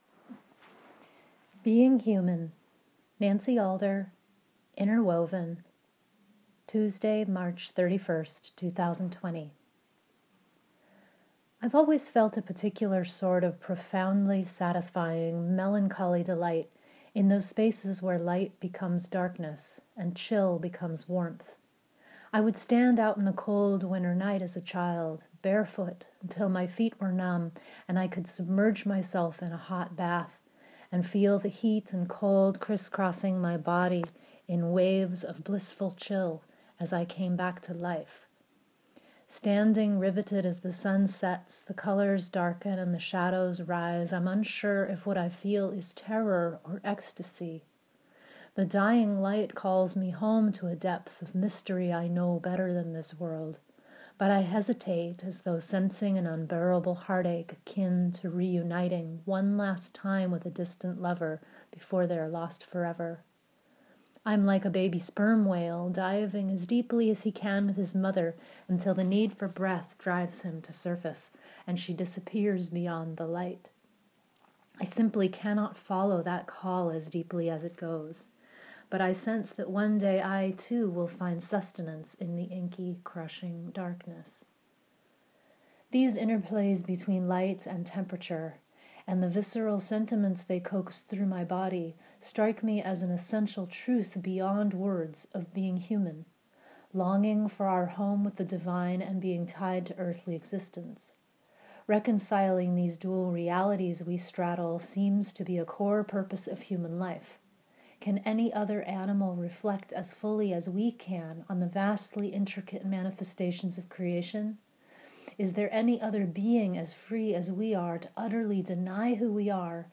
Let me read it to you!